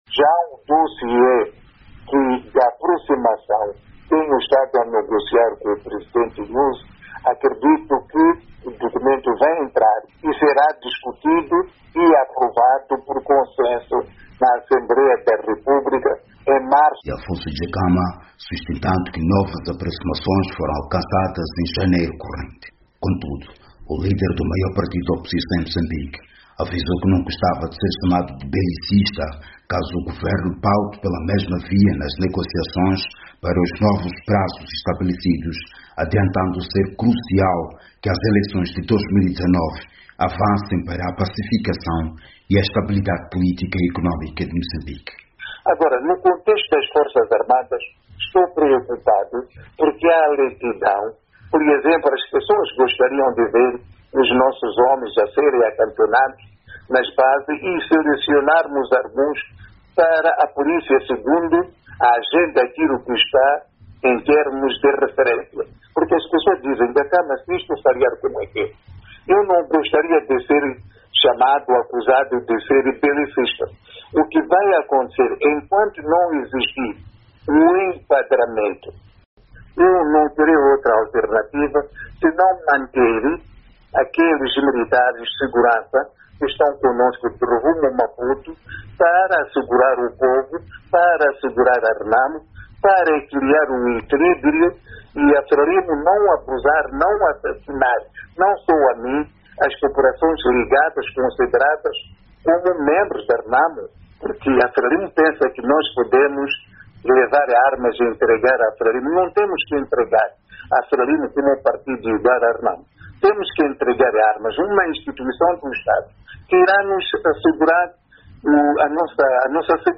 Dhlakama, que falava por telefone a partir da Gorongosa, onde se encontra escondido deste 2016, acusou o Governo de ter premeditado atrasos nas negociações de paz em 2017, arrastando o processo para este ano e prejudicando um novo acordo.